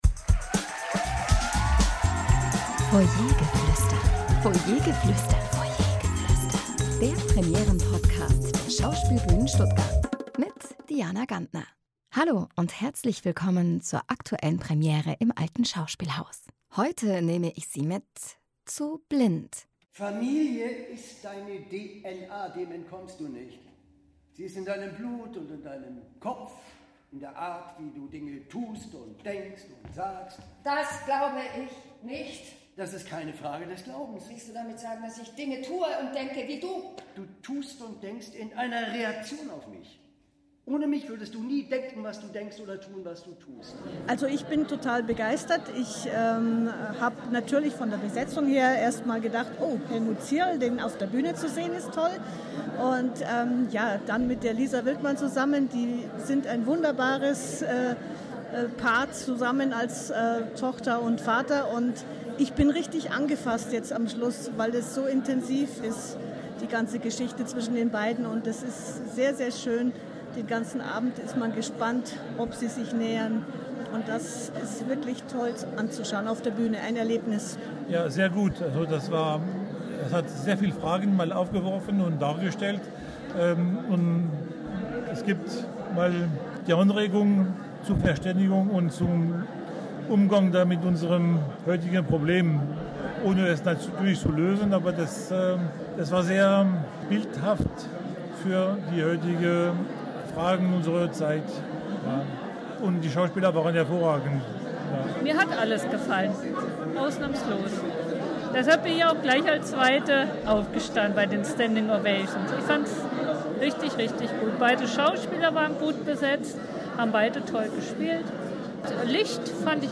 Publikumsstimmen zur Premiere von “Blind”